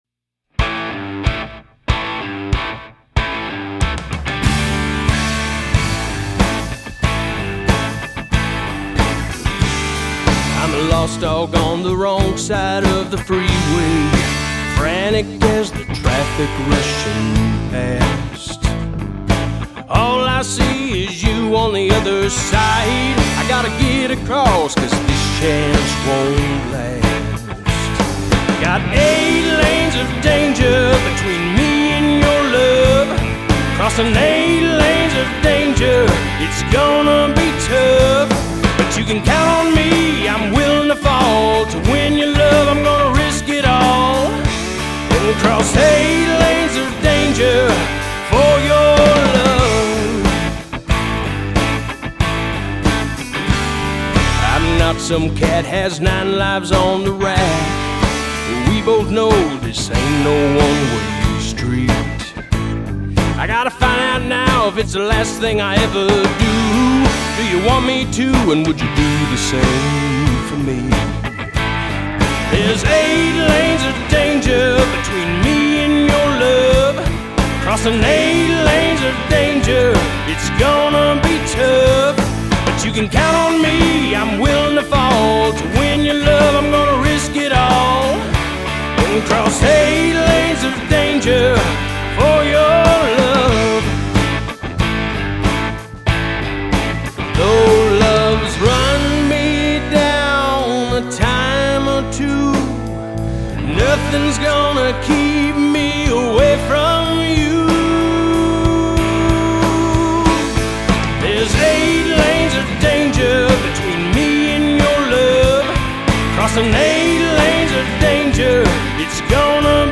“Eight Lanes of Danger” is a country song with perhaps a bit of rock thrown in.